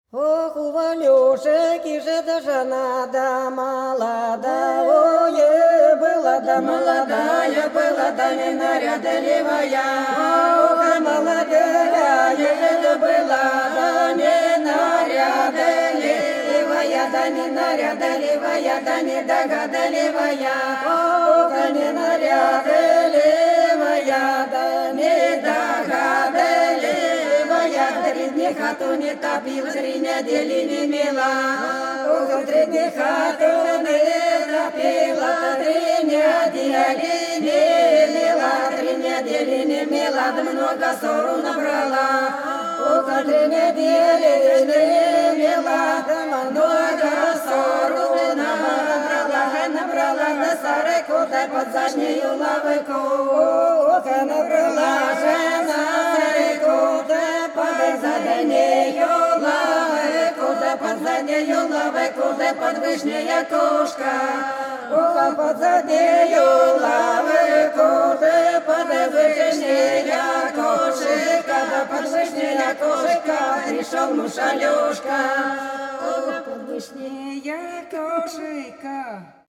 Белгородские поля (Поют народные исполнители села Прудки Красногвардейского района Белгородской области) У Ванюши жена молодая была - плясовая